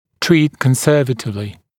[triːt kən’sɜːvətɪvlɪ][три:т кэн’сё:вэтивли]лечить консервативно